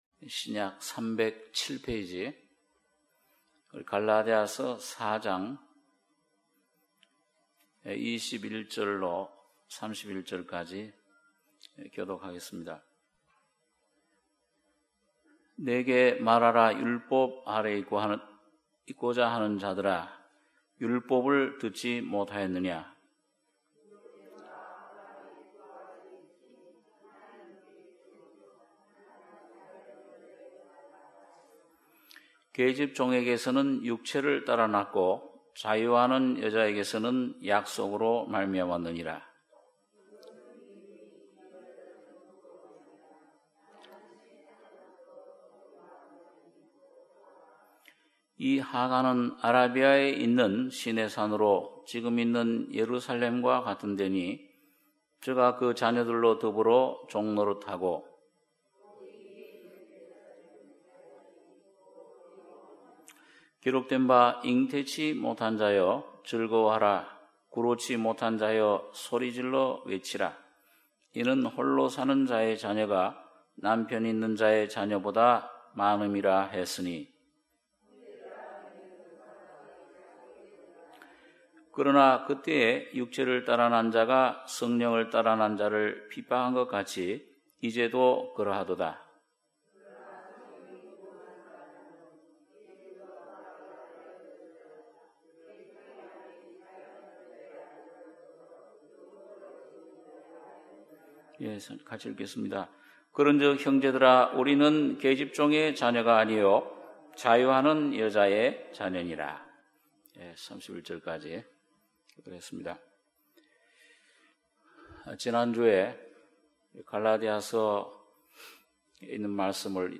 수요예배 - 갈라디아서 4장 21절-31절